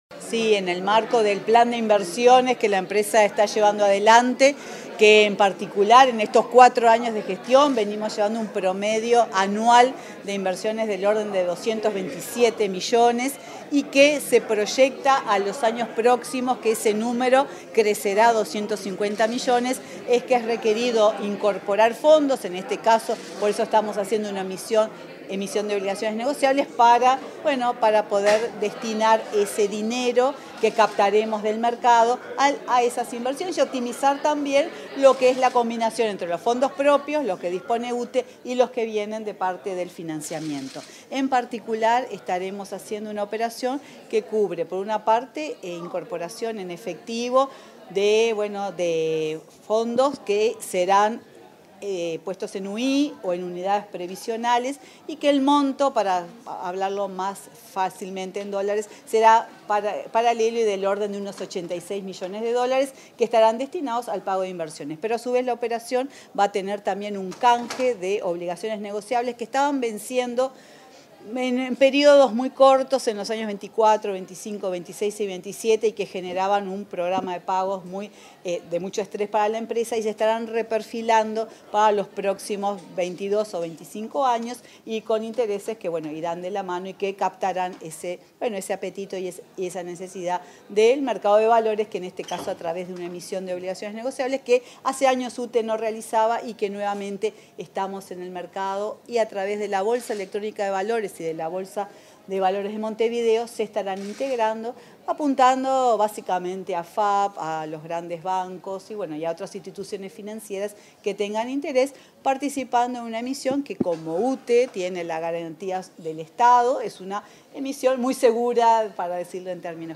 Declaraciones a la prensa de la presidenta de UTE, Silvia Emaldi
Declaraciones a la prensa de la presidenta de UTE, Silvia Emaldi 15/12/2023 Compartir Facebook X Copiar enlace WhatsApp LinkedIn Tras la presentación del programa de emisión de obligaciones negociables de la UTE, este 15 de diciembre, la presidenta de la empresa estatal, Silvia Emaldi, dialogó con la prensa.
Emaldi prensa.mp3